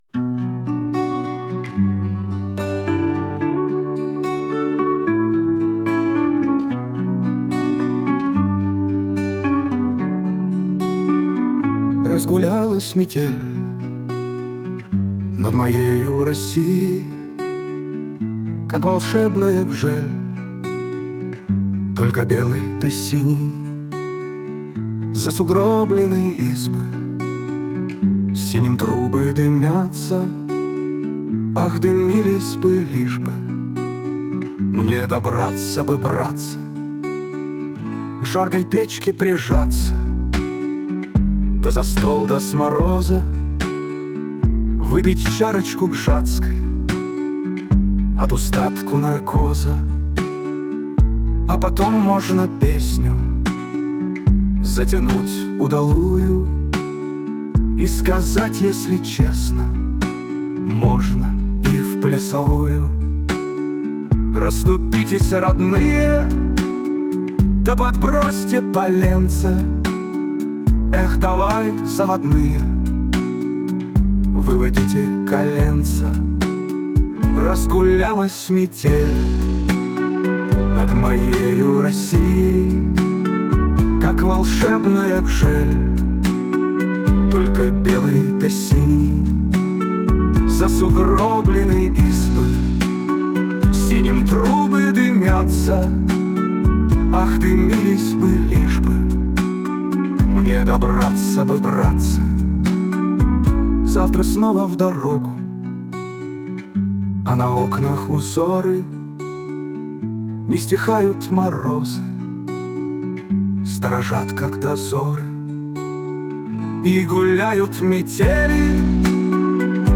• Аранжировка: Аi
• Жанр: Поп